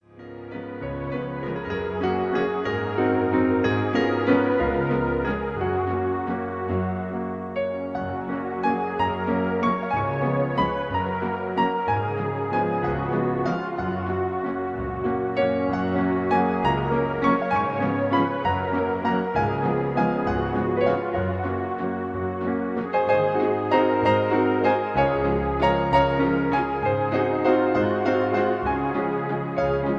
Schönes Lied im Musette-Stil